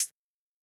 UHH_ElectroHatB_Hit-25.wav